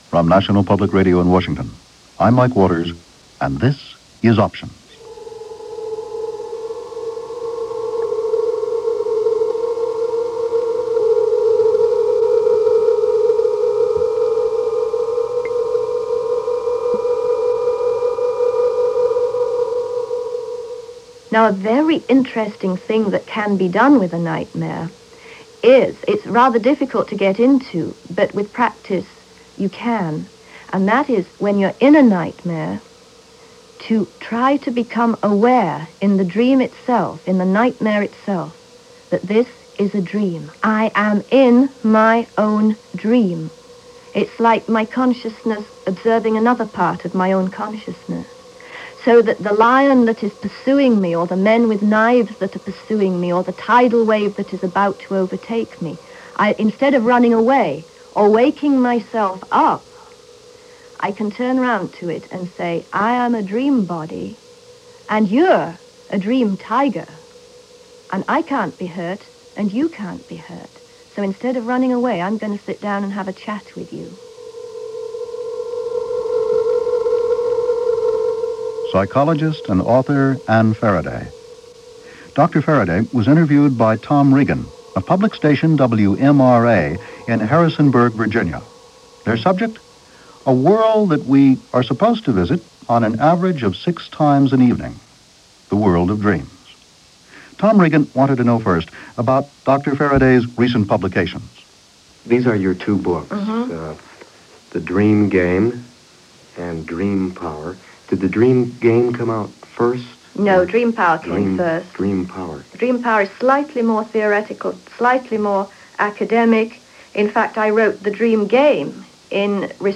NPR – Options: Dream Power – A conversaation with Dr. Ann Faraday